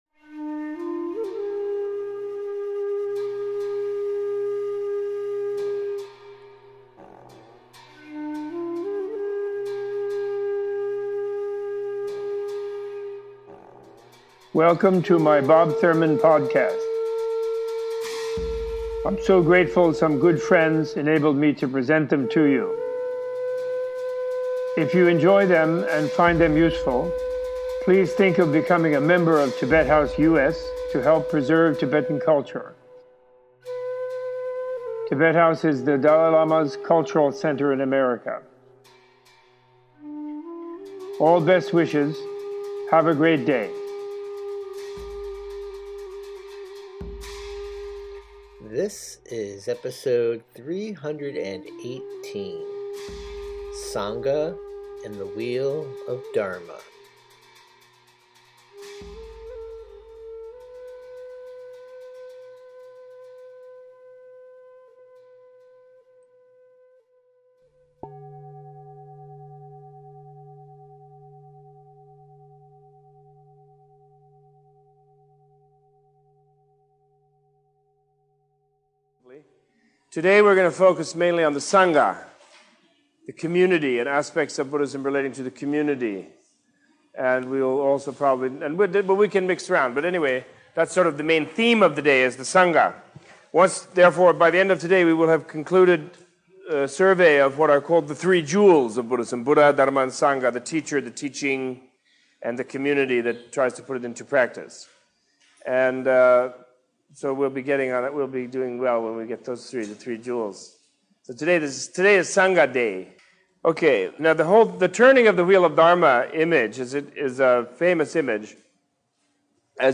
Opening with a discussion of the symbolism of the wheel of Dharma, Robert Thurman gives a teaching on the three jewels of Buddhism, focusing on the community of followers of Buddha and his teachings, known as the Sangha.
-Text From Better Listen Basic Buddhism This episode is an excerpt from the Better Listen “Basic Buddhism” Audio Course.